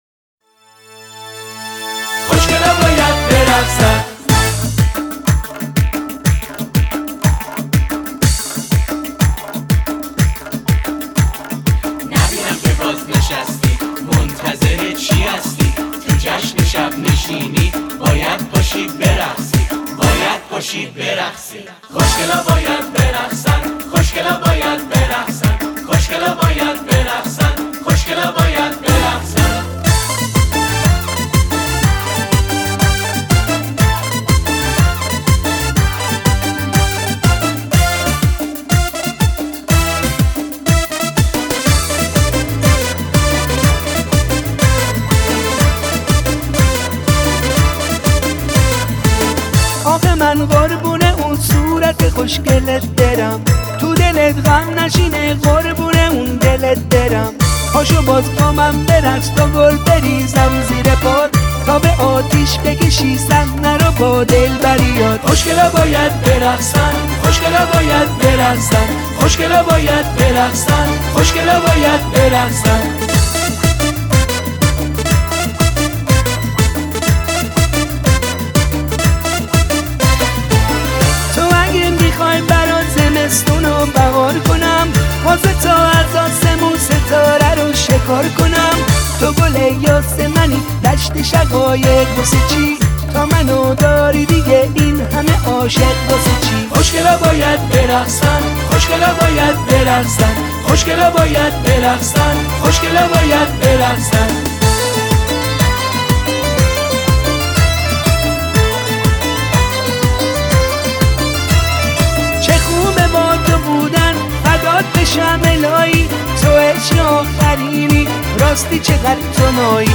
آهنگ شاد
رقص عروسی